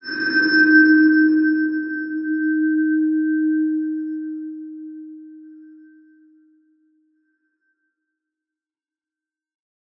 X_BasicBells-D#2-ff.wav